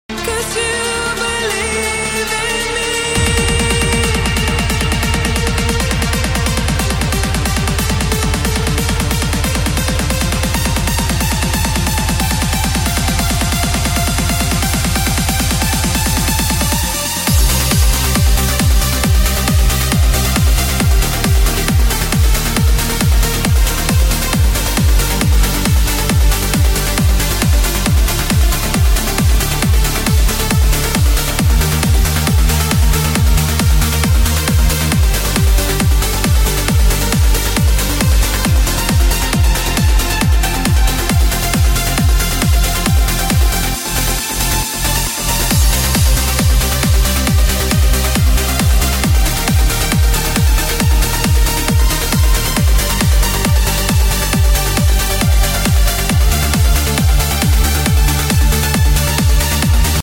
138 BPM